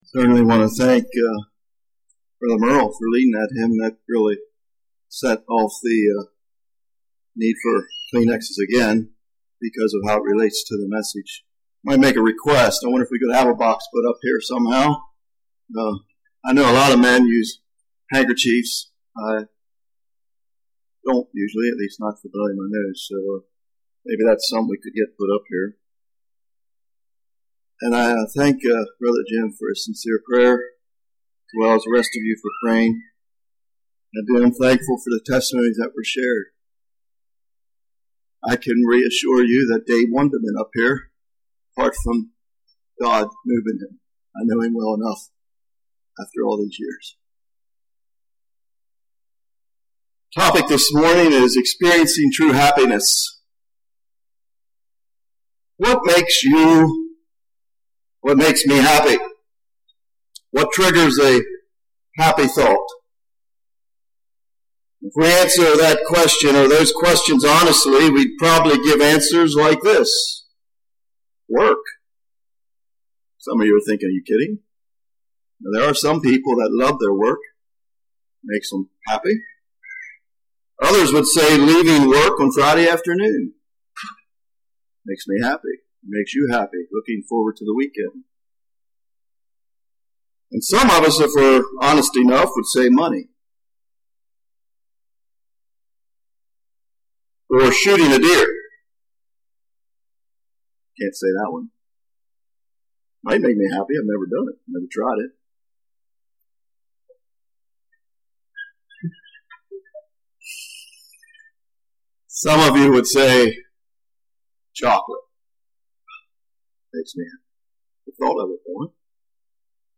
NOTE: Problems with audio in this message. Audio is not clear.